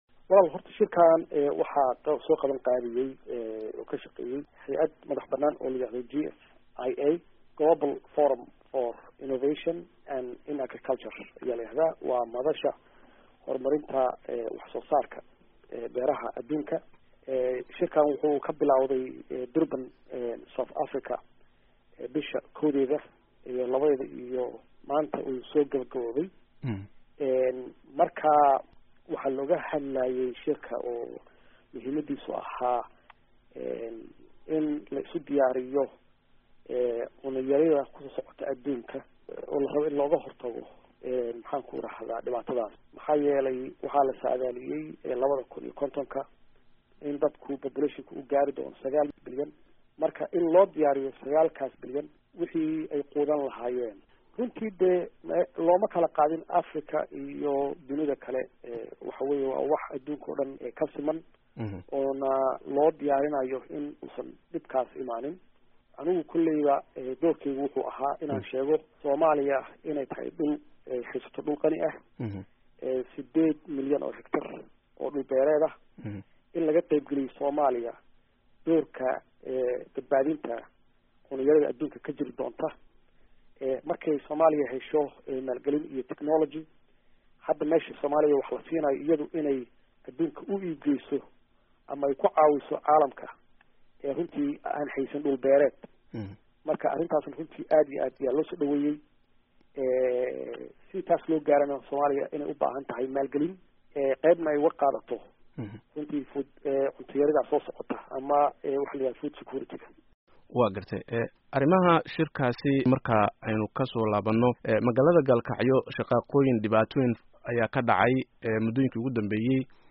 Wareysi: Farmaajo